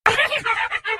ghost_is_vulnerable.ogg